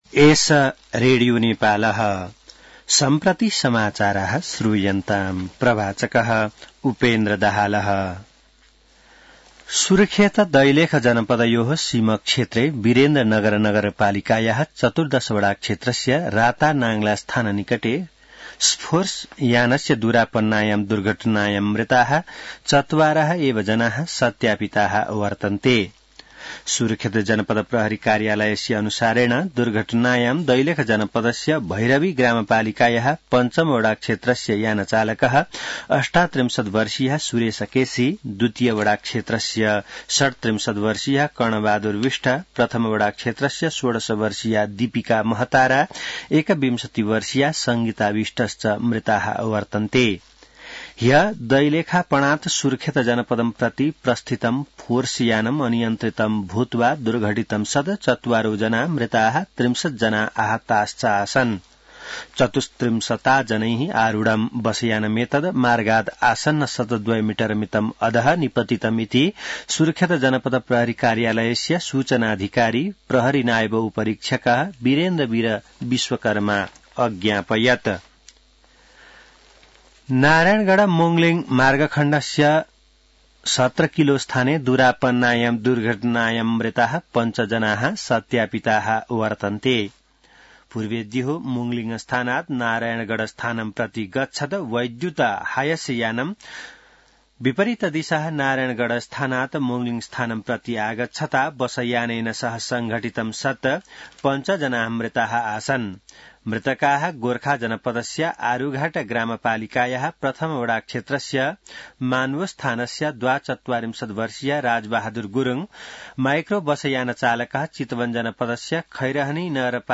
संस्कृत समाचार : २५ कार्तिक , २०८१